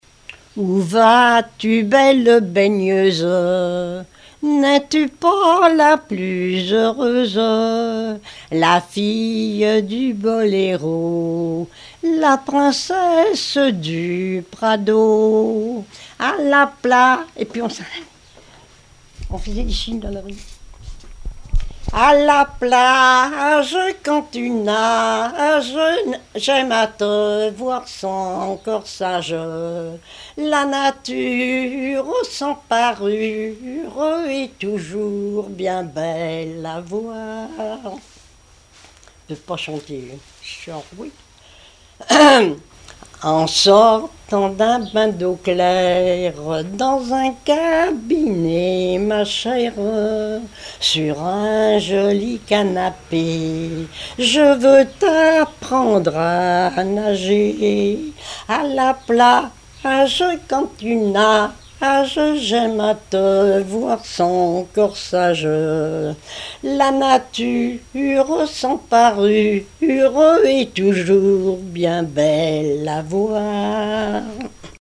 Genre strophique
témoigneges et chansons populaires
Pièce musicale inédite